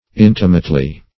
Intimately \In"ti*mate*ly\, adv.